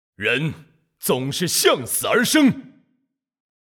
技能语音